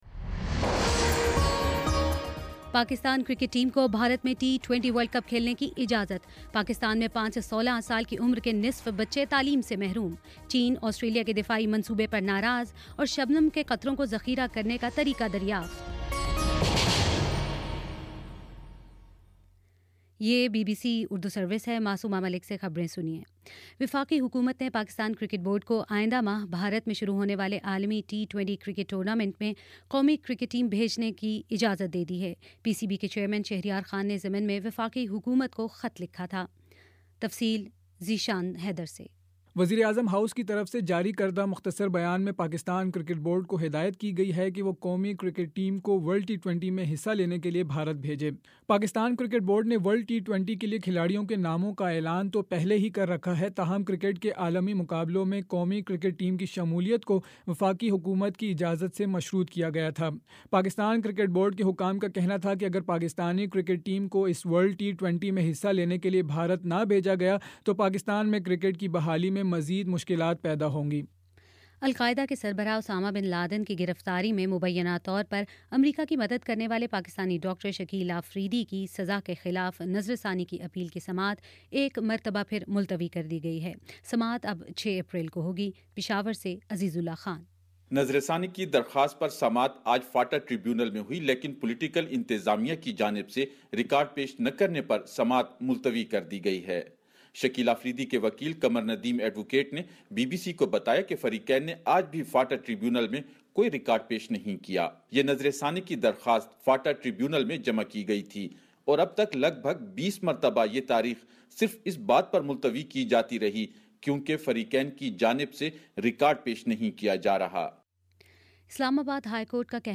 فروری 25 : شام پانچ بجے کا نیوز بُلیٹن